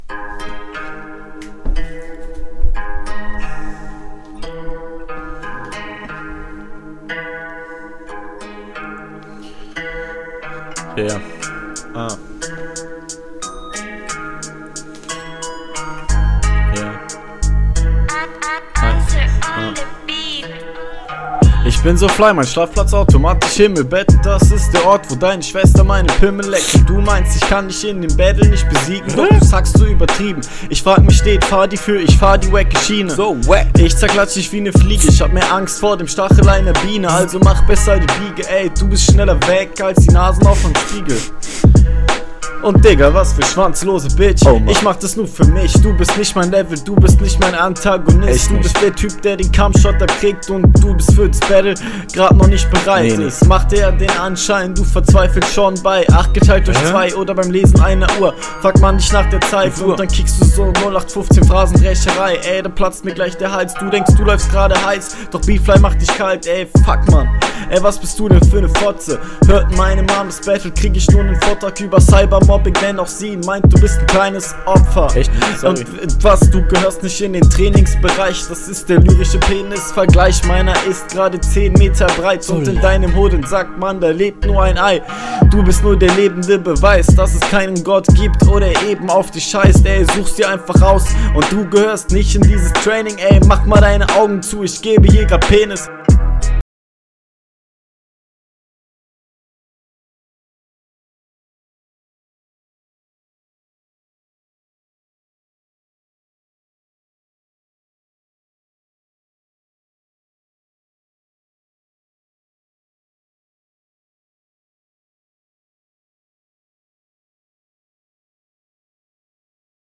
Mische sehr schlecht wegen der adlips und flow nunja echt nicht gut vorallem ist es …
Das ist nicht dein Beat das merkt man sofort. Flow unsicherer diesmal, du verschleppst zahlreich …